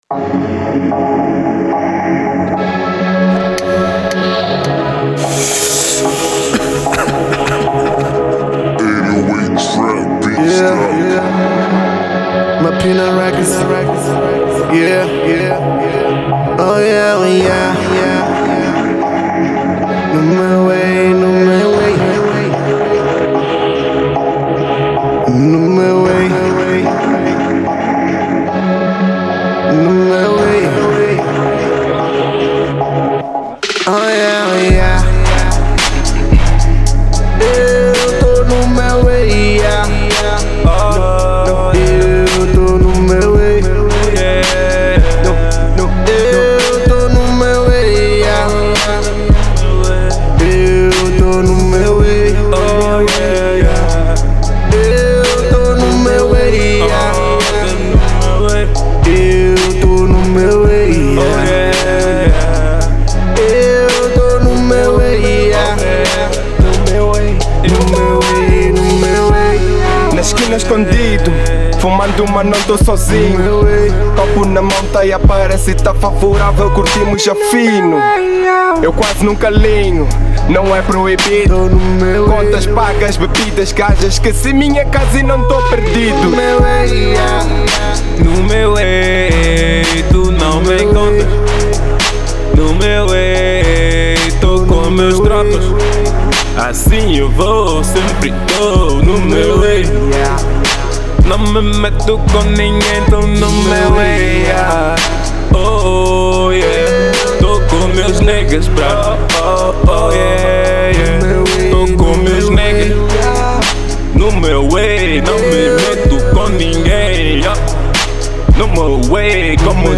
Genero: Rap